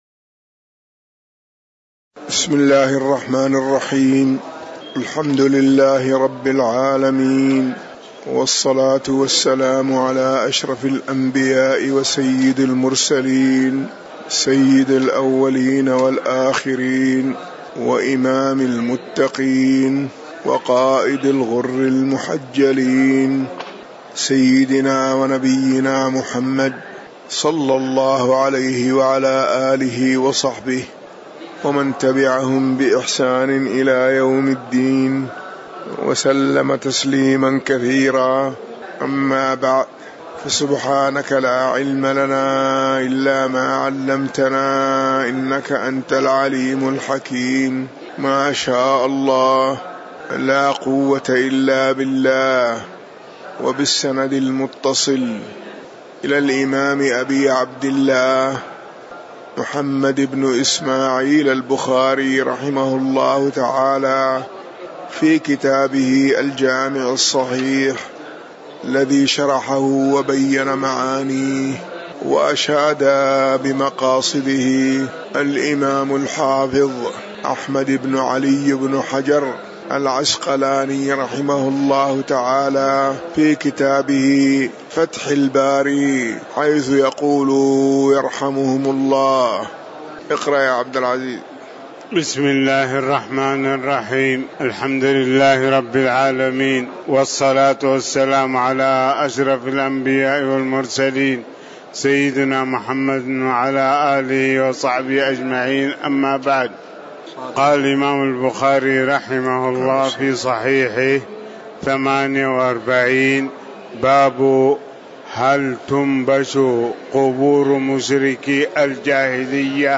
تاريخ النشر ٥ ذو القعدة ١٤٤٠ هـ المكان: المسجد النبوي الشيخ